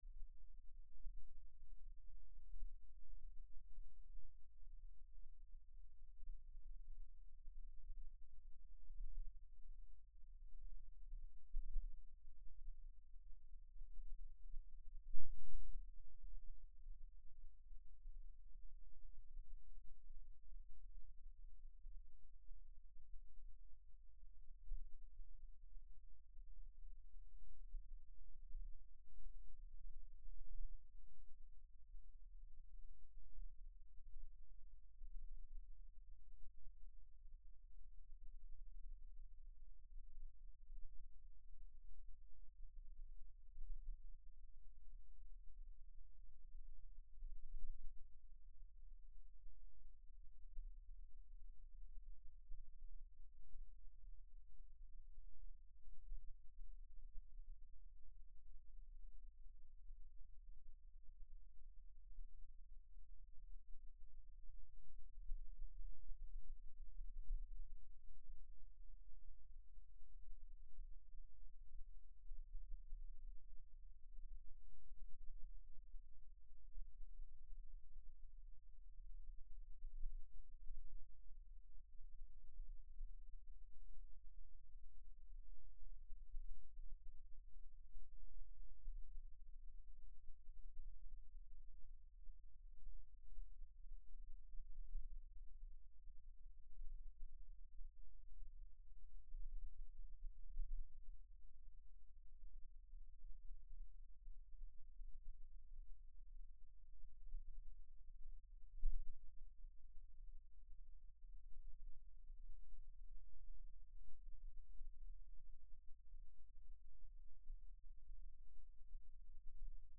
Stereo wave recorded during the earthquake, from 16:00 to 20:00, left channel geophone, right channel Marconi antenna: 13FEB15-1600_E.wav as detected from station 01 - CUMIANA (TO), NW Italy